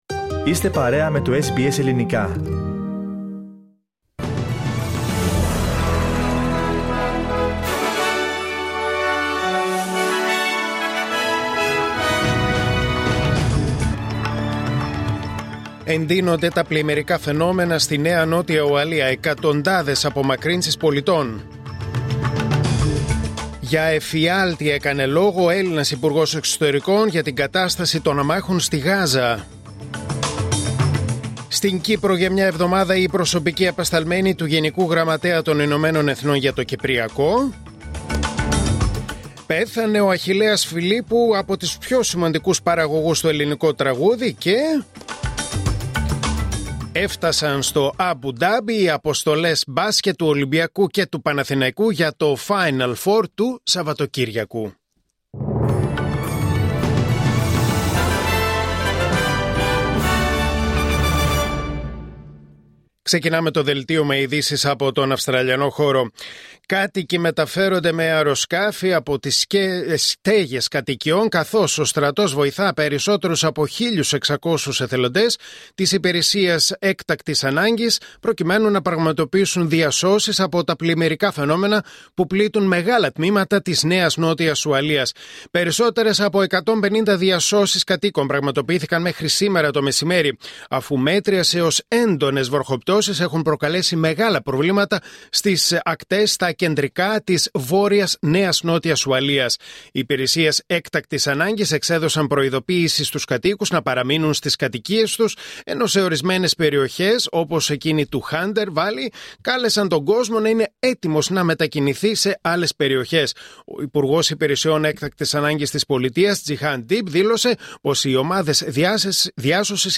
Δελτίο Ειδήσεων Τετάρτη 21 Μαΐου 2025